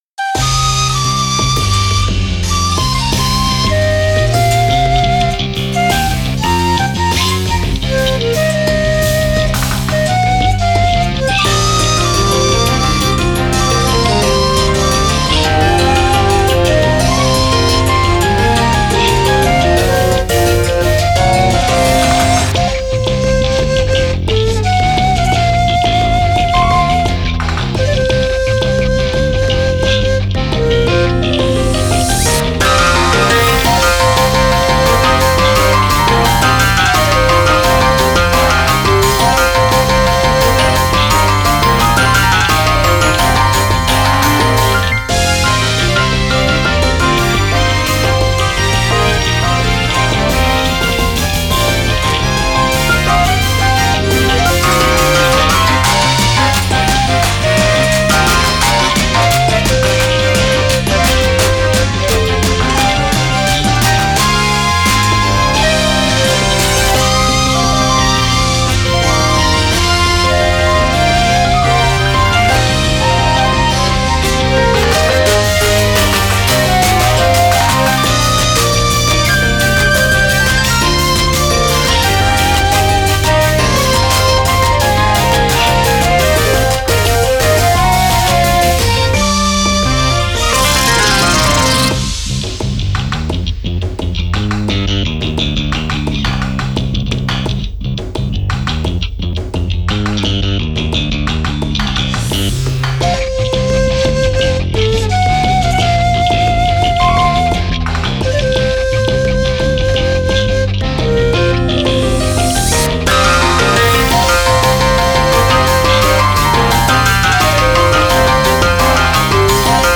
かっこいい雰囲気の曲です。
尺八が……！
タグ かっこいい 　和風